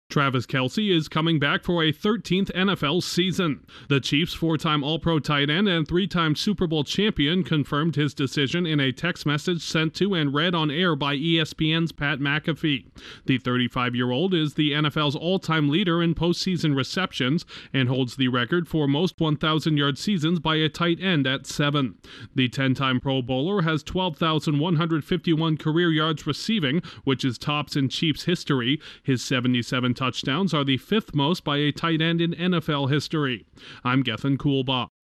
One of the NFL’s most decorated tight ends has opted not to retire. Correspondent